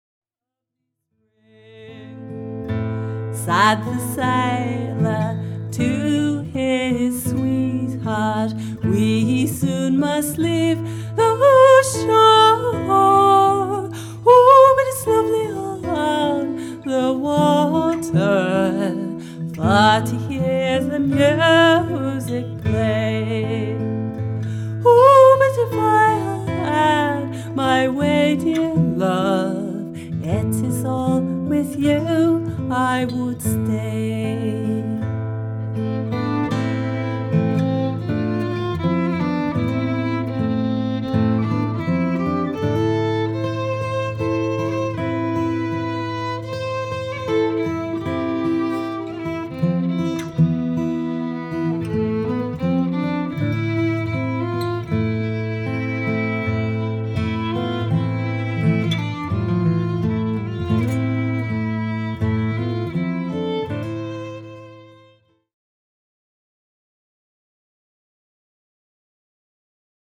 indeed: both exuberantly intricate and